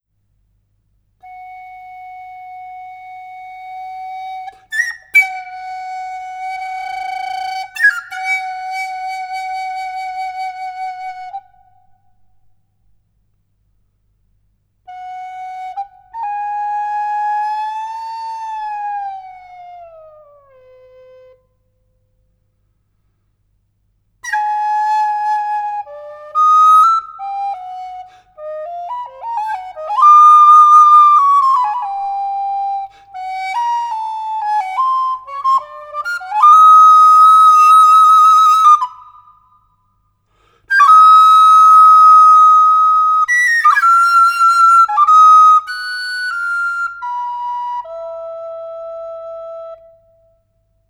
For soprano or tenor recorder